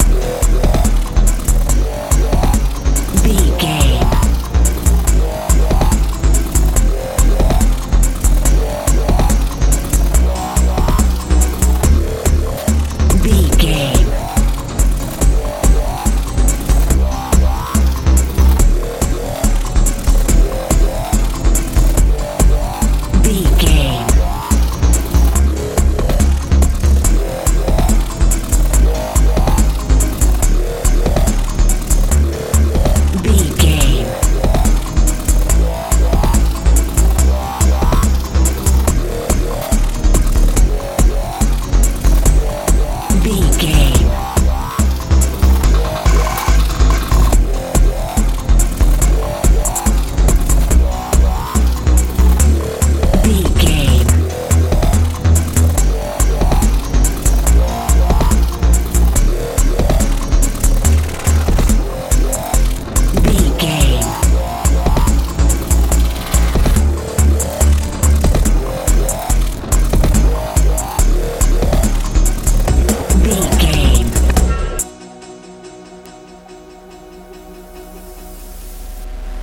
dance feel
Ionian/Major
strange
futuristic
synthesiser
bass guitar
drums
high tech
heavy
mechanical